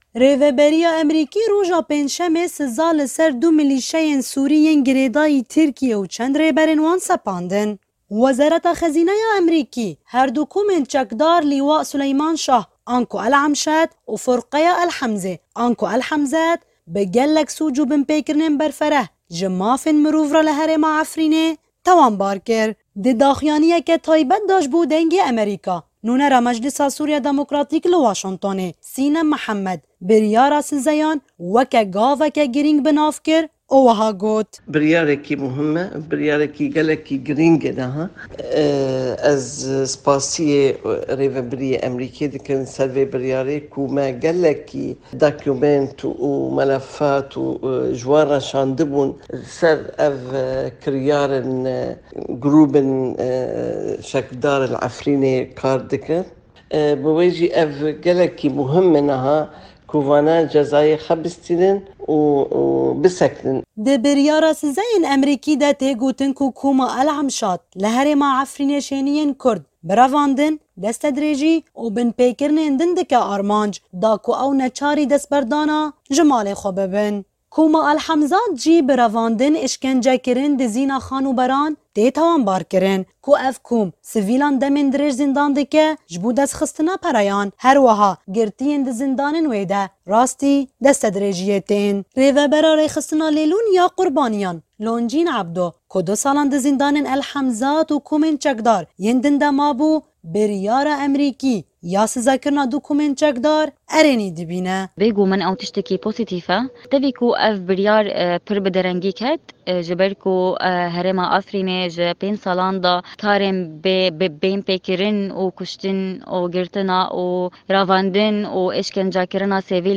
Raporta Efrîn_NR_08_18_2023_Sizayên_Amerîkî_Komên_Çekdar.mp3